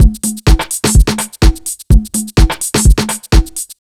126BEAT1 1-R.wav